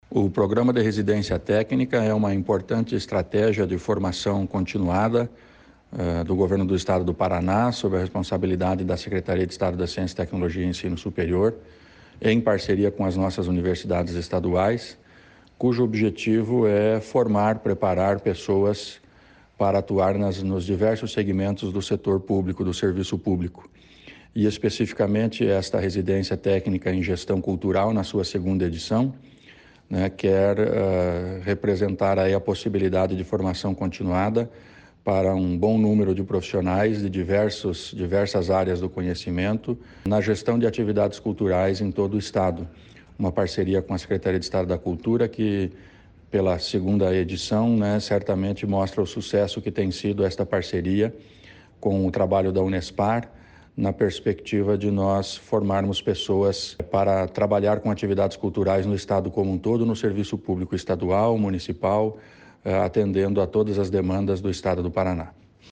Sonora do secretário da Ciência, Tecnologia e Ensino Superior do Paraná, Aldo Bona, sobre o programa de Residência Técnica em Gestão Cultural